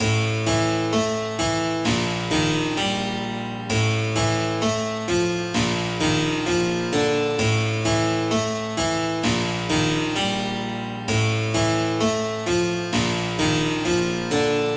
Famille : cordes pincées
Descriptif : d’aspect, cet instrument ressemble à un petit piano à queue, saut que ce sont des plectres (petits becs en bois) qui viennent pincer les cordes.
Clavecin